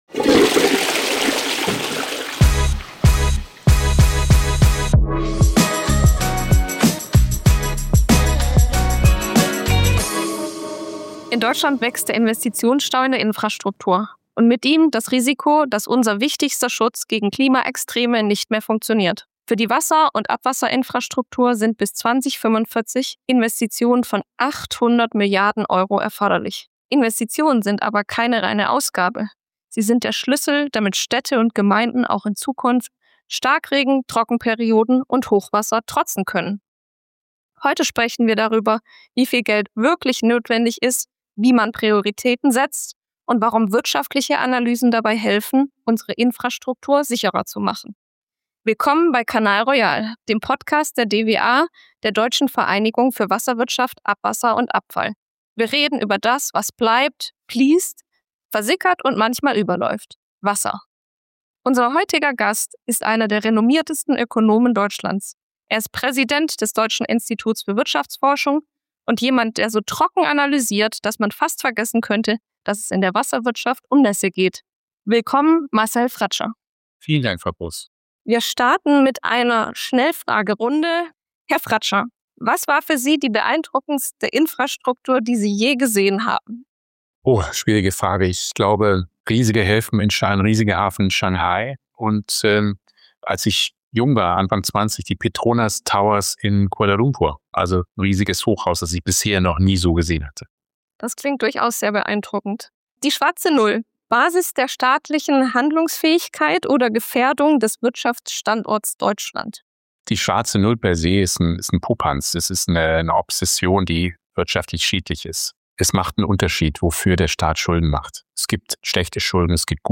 Ein Gespräch über trockene Zahlen, nasse Realitäten und die infrastrukturelle Verantwortung gegenüber kommenden Generationen.